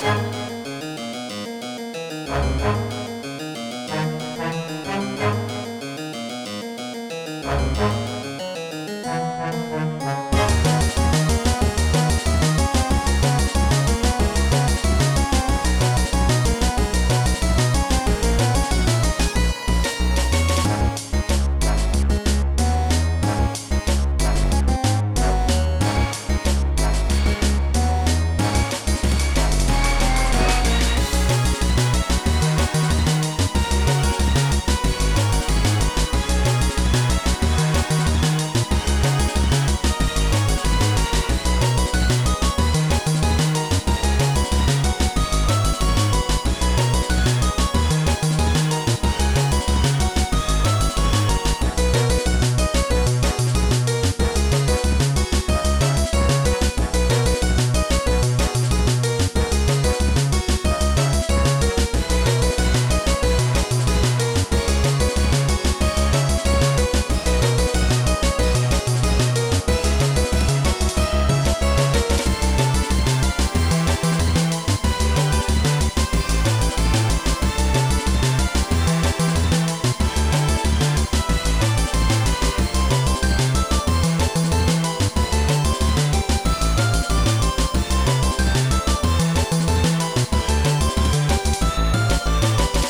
SGM Soundfont
Squidfont Orchestral Soundfont
NES VST 1.2
It’s a unique melody for a Roblox game!
Its good, though I would make some parts of the melody more singable or hummable(y’know?) seems like a lot of notes, and itll be hard to remember, simplify it a little.
And add a better ending, as its very abrupt.
i don’t really like how happy it sounds. although it really depends on the gameplay
The forum site doesn’t have a “loop” so the song ends abruptly, but in-game it has a wrapped remainder loop which makes it way softer.